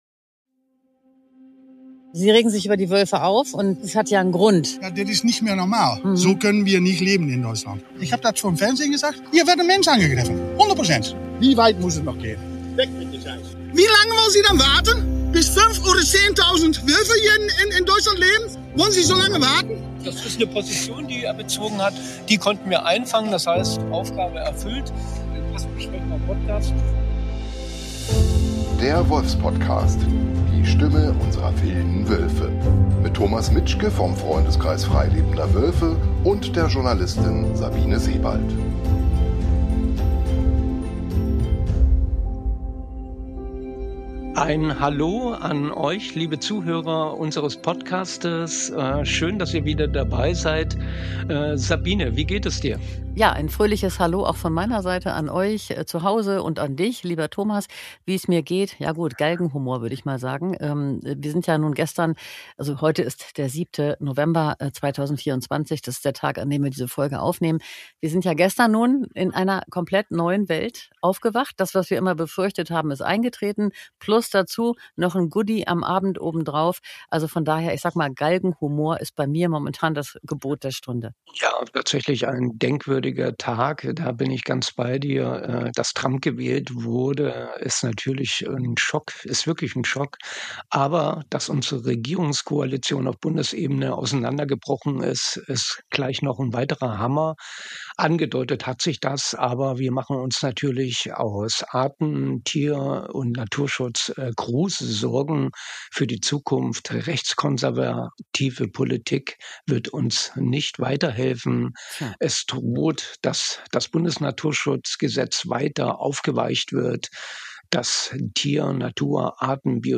30: Der Wolfspodcast vor Ort in der Uckermark ~ Der Wolfspodcast Podcast